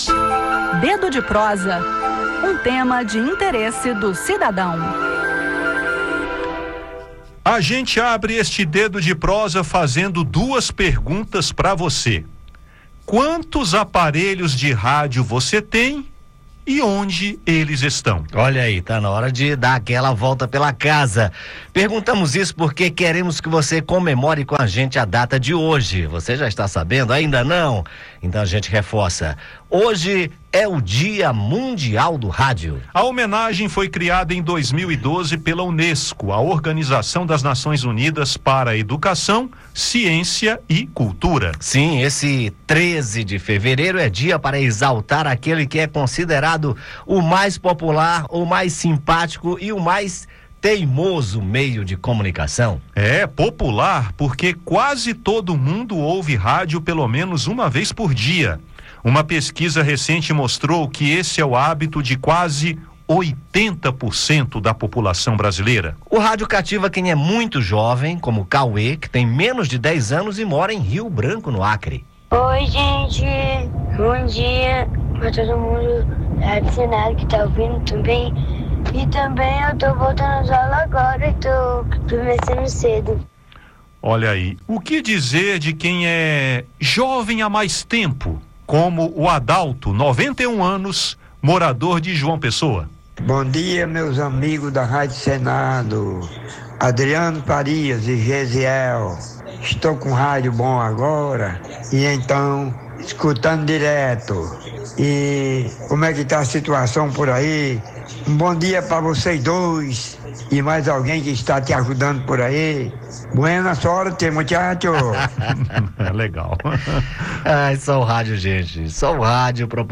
No Dedo de Prosa, exploramos a singularidade do rádio, sua capacidade de adaptação diante das novas tecnologias e sua relevância contínua. Além disso, confira as homenagens especiais de ouvintes da Rádio Senado a esse meio de comunicação tão querido.